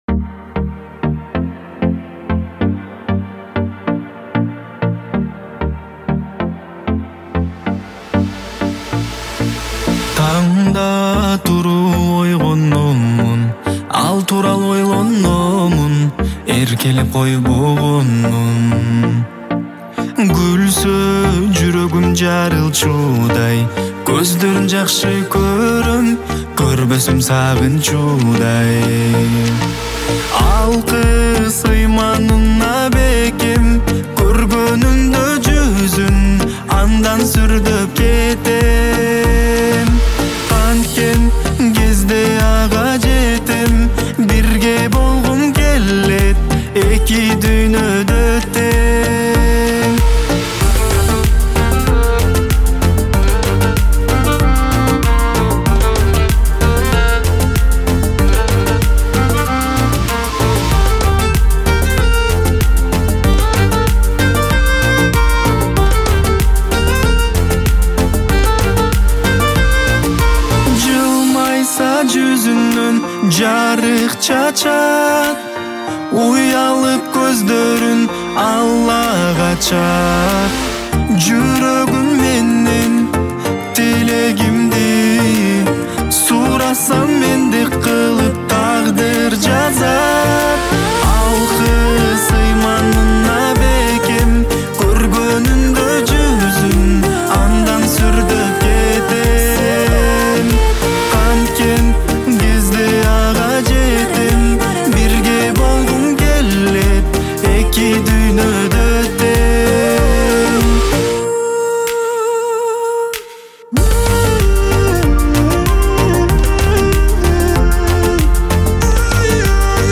• Киргизские песни